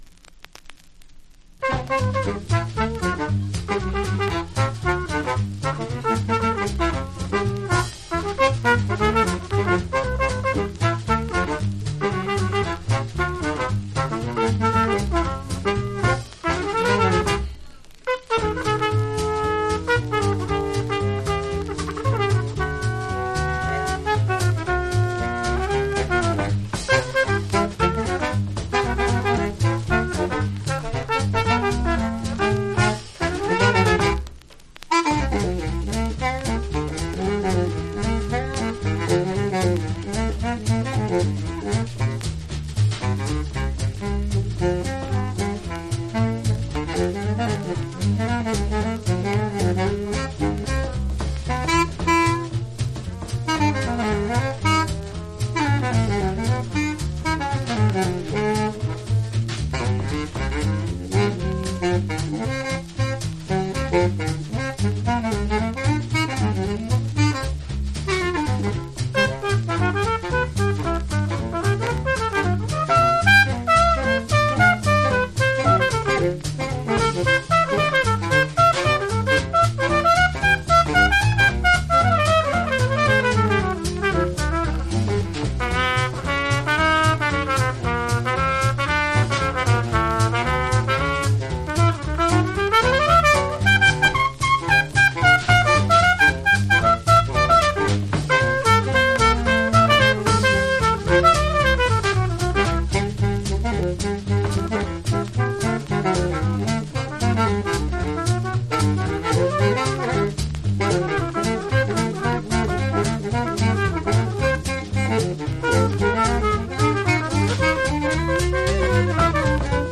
（小傷によりチリ、プチ音ある曲あり）
Genre US JAZZ